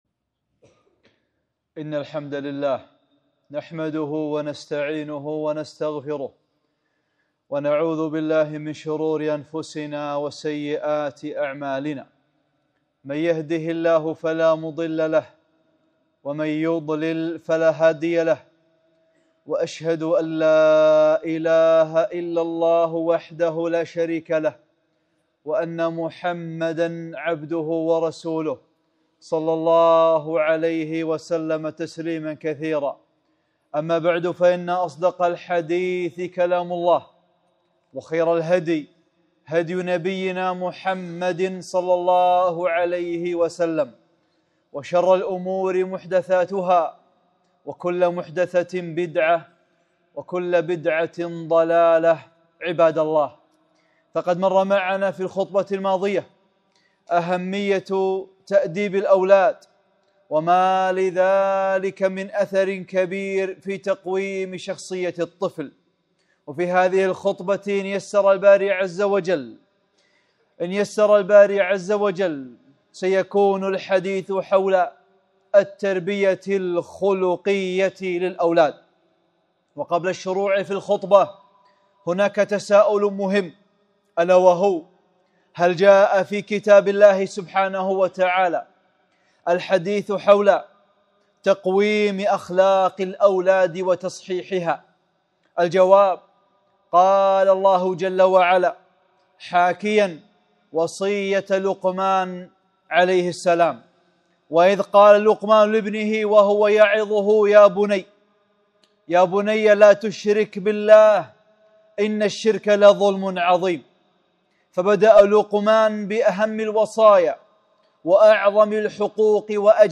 (26) خطبة - الاعتقاد الصحيح، الاعتراف بالنعم